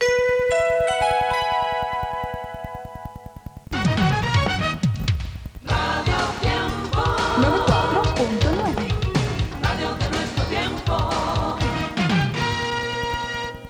Sintonia i indicatiu.